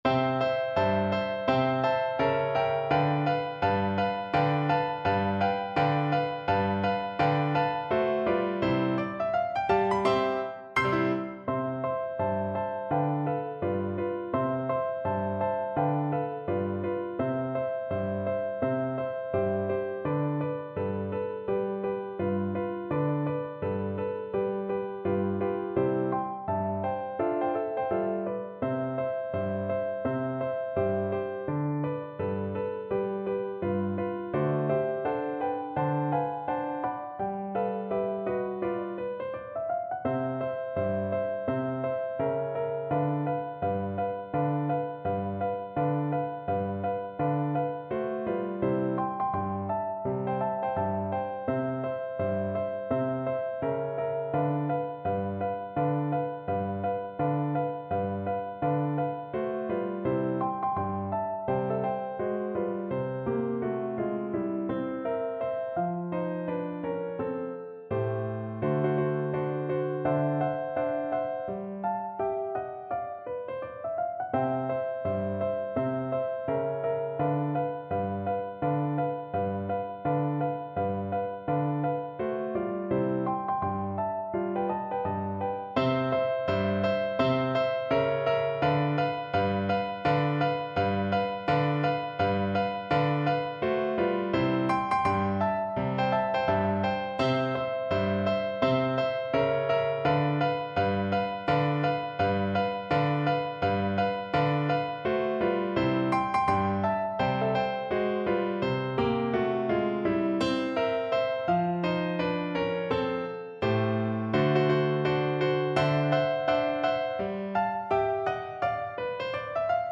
2/2 (View more 2/2 Music)
~ = 168 Moderato
Pop (View more Pop Flute Music)